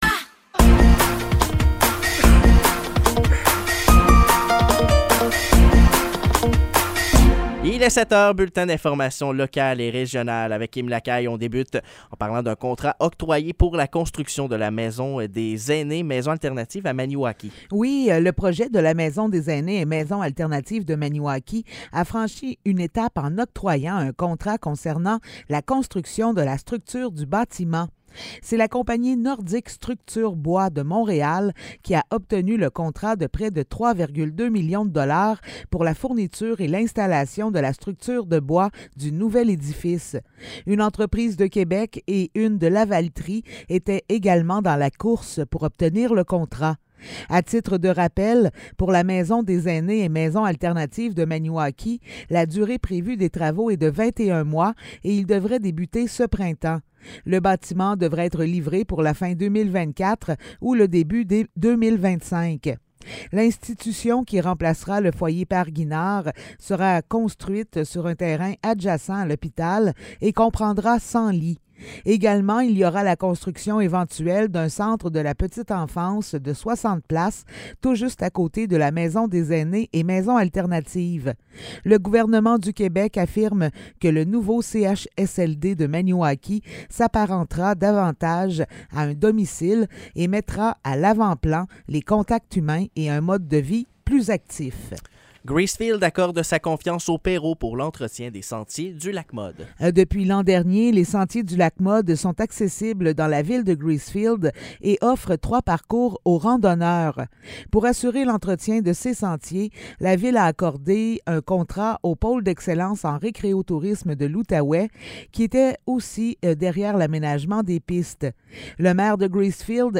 Nouvelles locales - 18 avril 2023 - 7 h